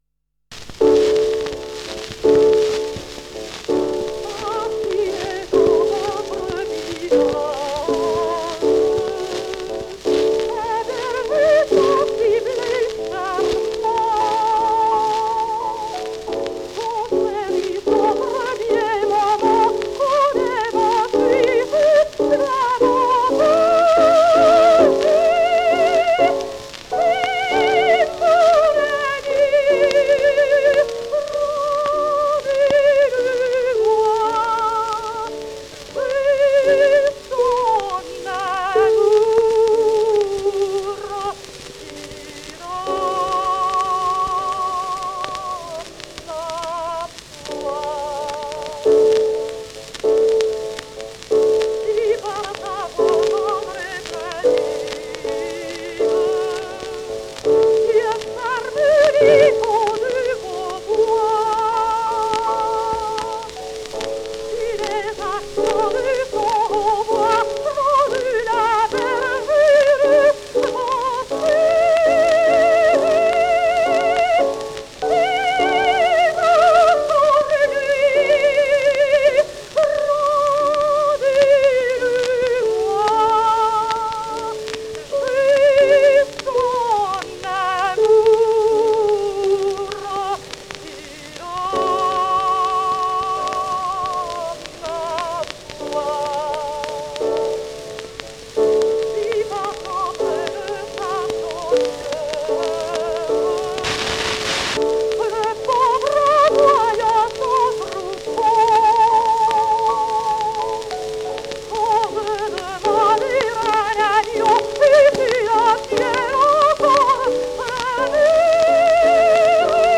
Анжелика Пандольфини (Angelica Pandolfini) (Сполето, 21 августа 1871 - Ленно, 15 июля 1959) - итальянская певица (сопрано).